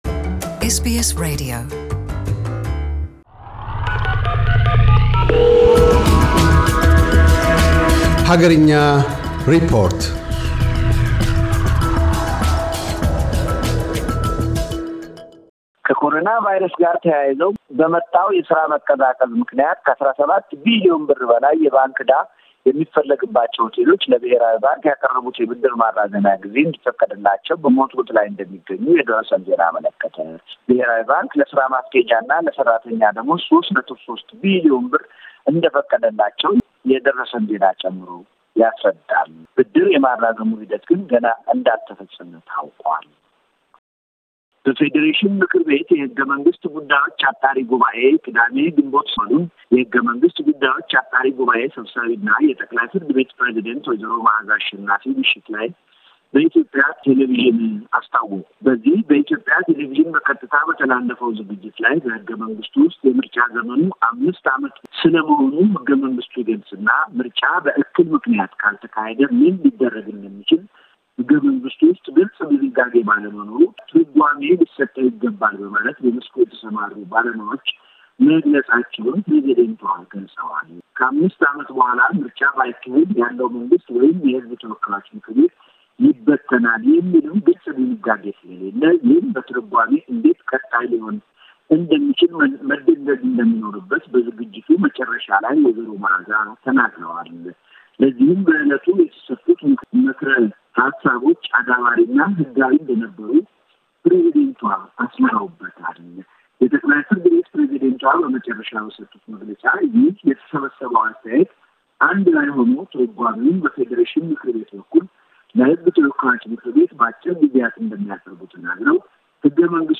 አገርኛ ሪፖርት - በኮቪድ - 19 ወረርሽኝ መከሰት ምክንያት ገበያቸው ተቀዛቅዞ ዕዳ የበረከተባቸው የኢትዮጵያ ሆቴሎች ለብሔራዊ ባንክ የብድር ማራዘሚያ መጠየቃቸውን ቀዳሚ ትኩረቱ አድርጓል።